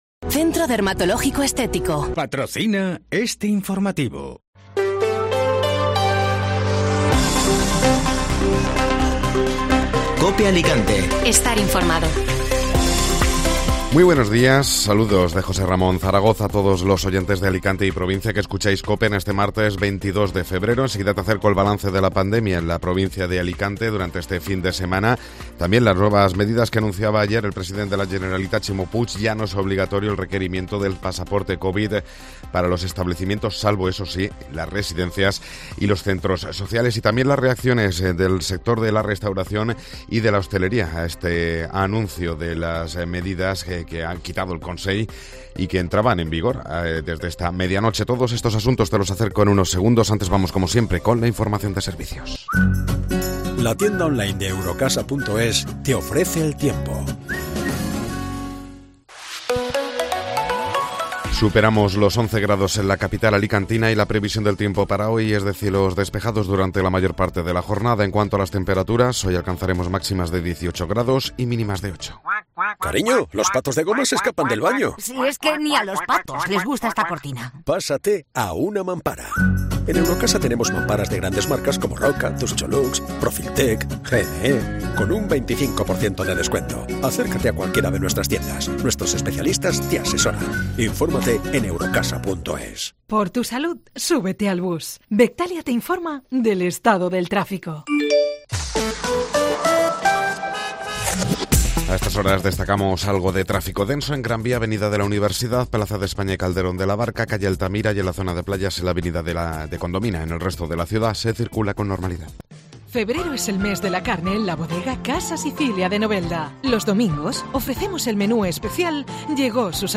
Informativo Matinal (Martes 22 de Febrero)